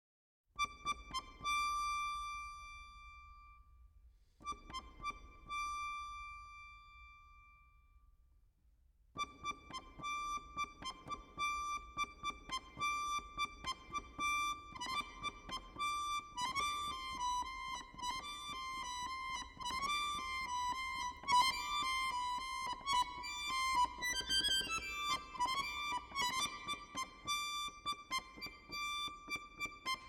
für Bajan und Percussion 11:15 € 1,45